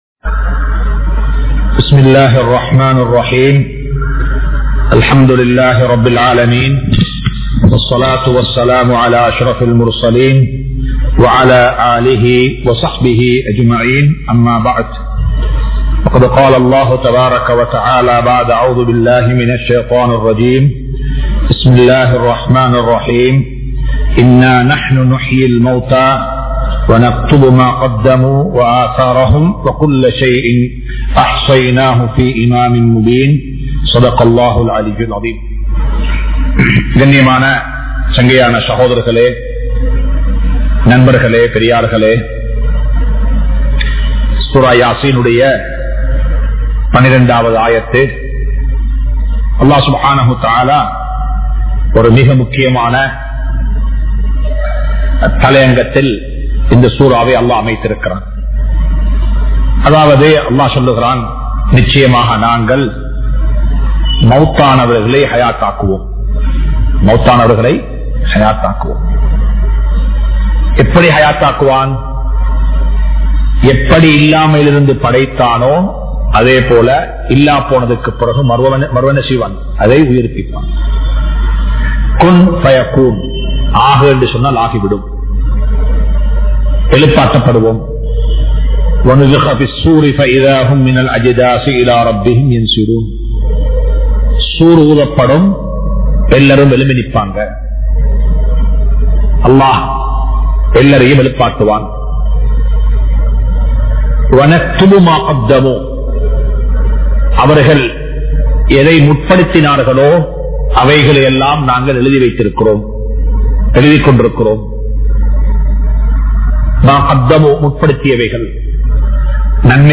Surah Yaseen Verse 12 | Audio Bayans | All Ceylon Muslim Youth Community | Addalaichenai